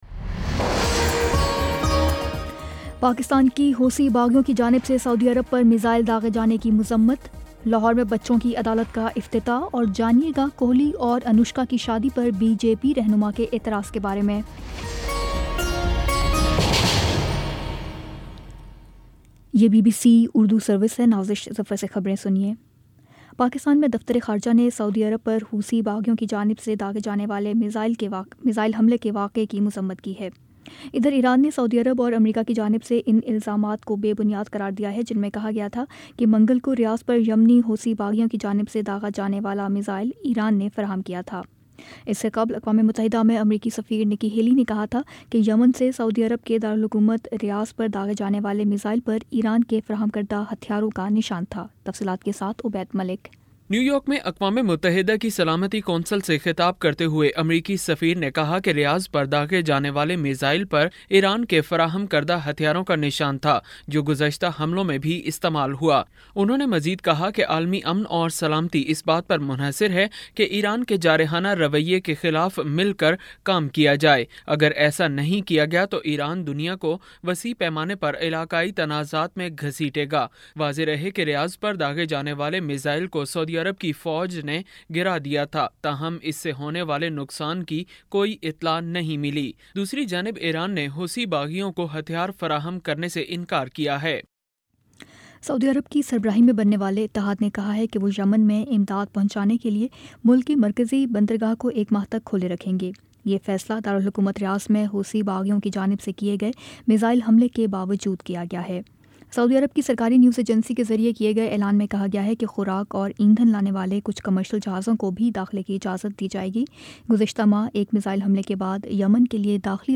دسمبر 20 : شام سات بجے کا نیوز بُلیٹن